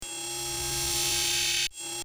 効果音
通常時